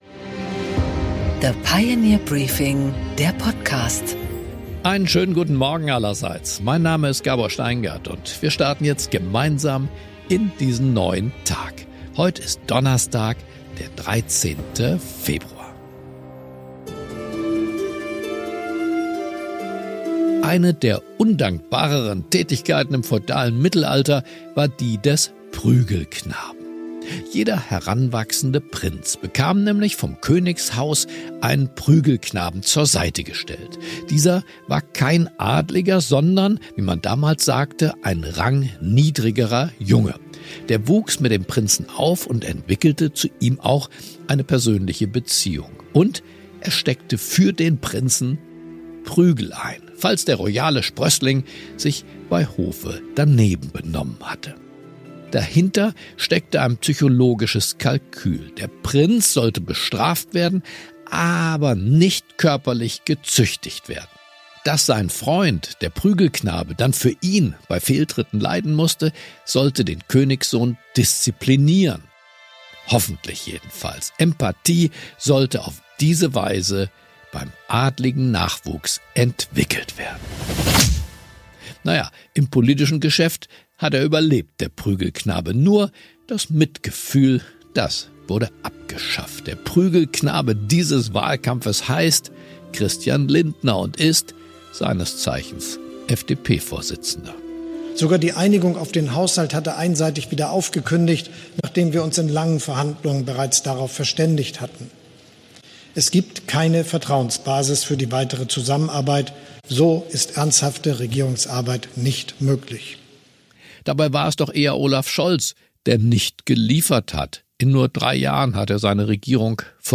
Gabor Steingart präsentiert das Pioneer Briefing
Im Interview: Jörg Schönenborn, ARD-Wahlmoderator und WDR-Programmdirektor für Information, Fiktion und Unterhaltung, spricht mit Gabor Steingart über den Wahlkampf, Demoskopie und die Stimmung der Deutschen.